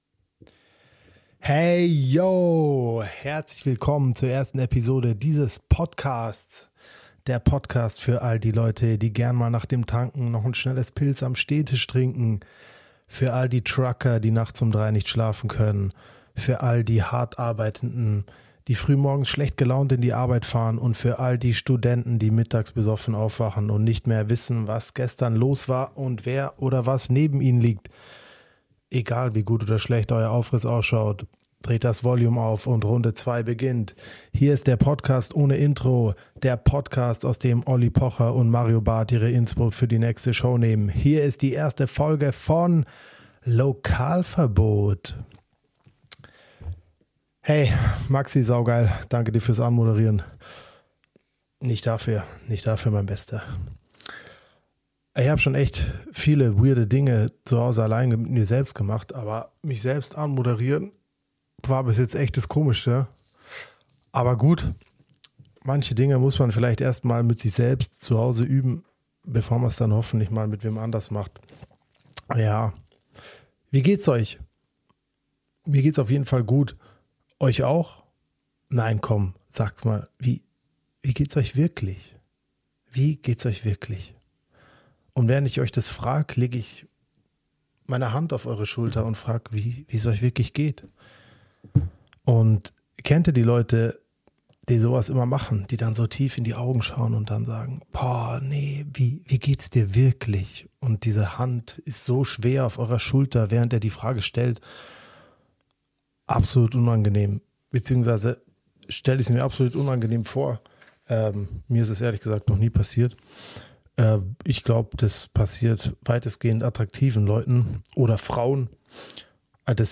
Da hatte ich noch sehr viel Respekt vorm Mikro.